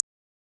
Plugg Hat 2(Official).wav